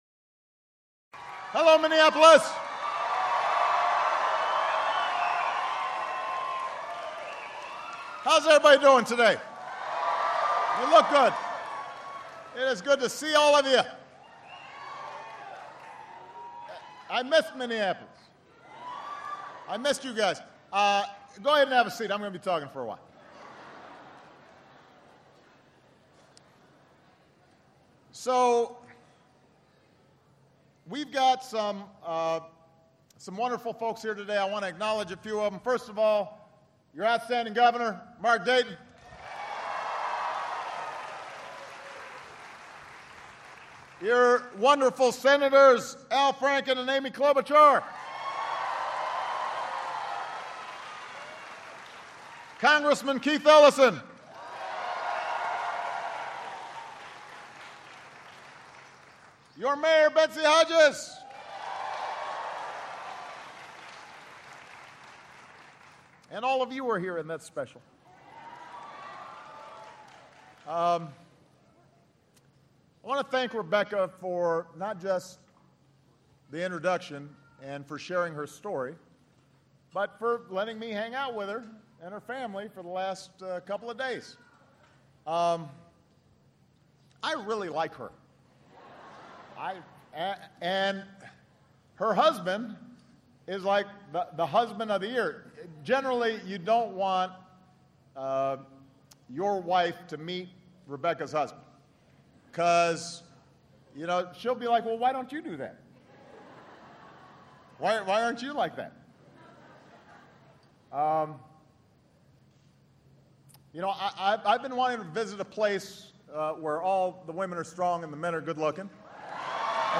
He asks Republicans in Congress to work with him on immigration reform and to present ideas that will create jobs and help the middle class. Obama uses the occasion to kick off a series of White House "day-in-the-life" summer trips in which the president will meet with Americans across the country. Held at Lake Harriet in Minneapolis, Minnesota.